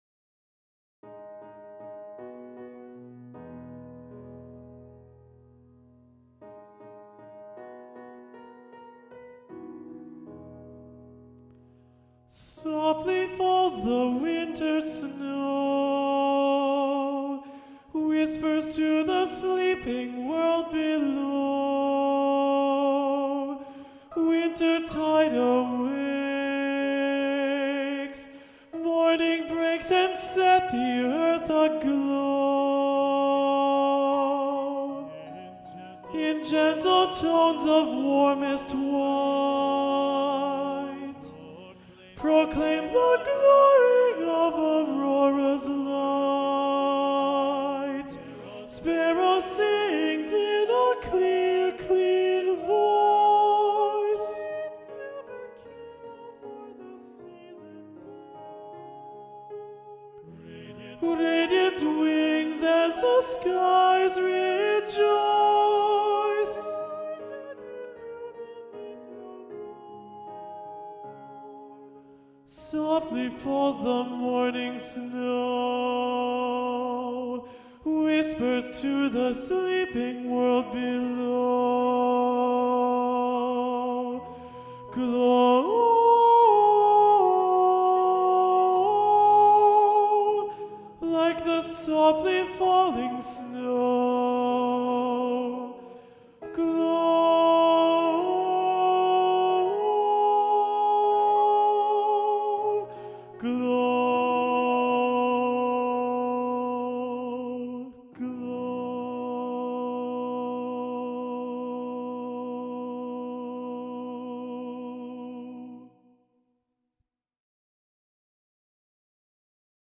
- Chant normalement à 4 voix mixtes SATB + piano
SATB Alto Predominant